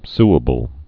(sə-bəl)